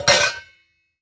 metal_lid_movement_impact_05.wav